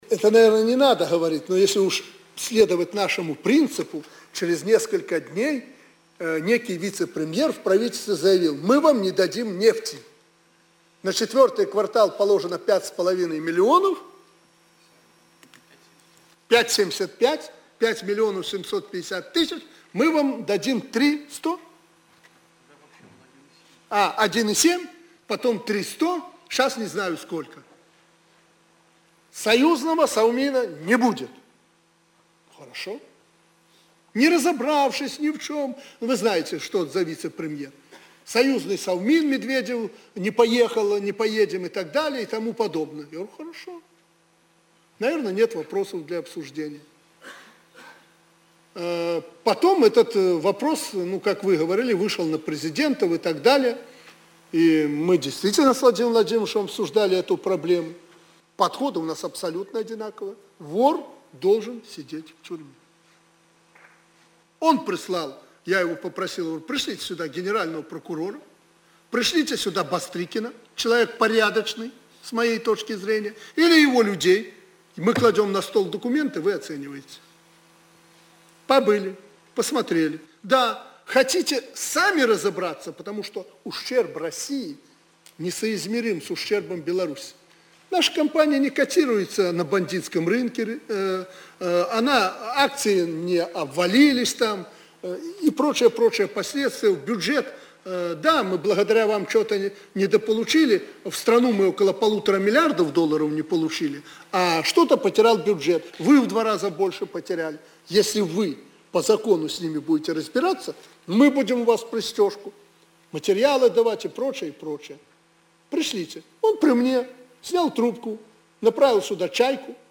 Прэсавая канфэрэнцыя Аляксандра Лукашэнкі для расейскіх журналістаў. Менск, 11 кастрычніка 2013